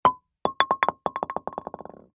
Звуки боулинга
Звук упавшей кегли на пол